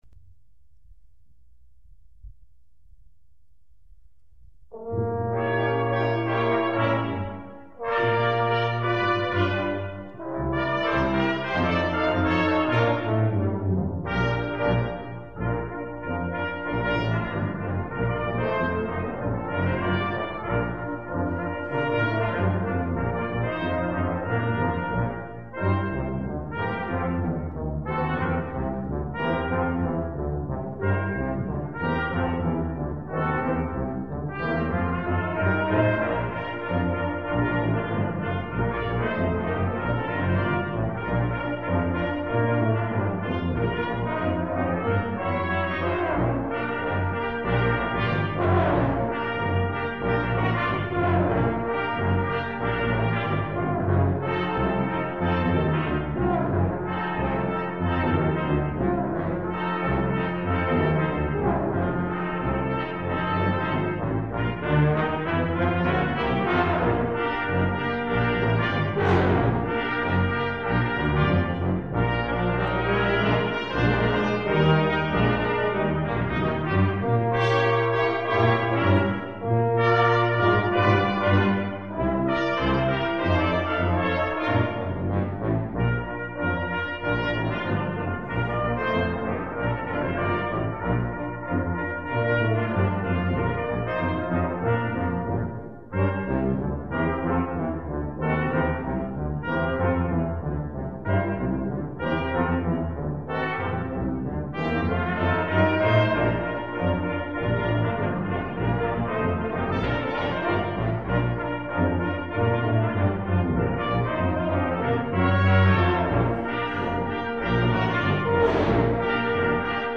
(aus dem Konzert in der Christuskirche 2007)